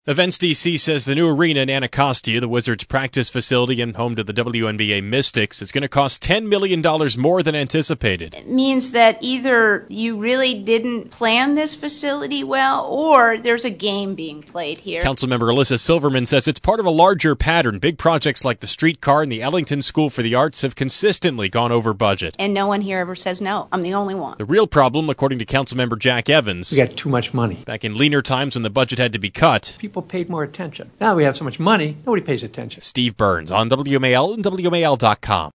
At a hearing on Monday, Councilmembers grilled Events D.C. on plans for its new arena, slated to become the Wizards’ new practice facility and the new home for the WNBA’s Washington Mystics.